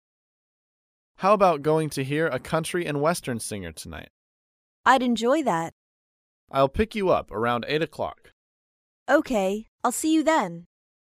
在线英语听力室高频英语口语对话 第74期:外出听演唱会的听力文件下载,《高频英语口语对话》栏目包含了日常生活中经常使用的英语情景对话，是学习英语口语，能够帮助英语爱好者在听英语对话的过程中，积累英语口语习语知识，提高英语听说水平，并通过栏目中的中英文字幕和音频MP3文件，提高英语语感。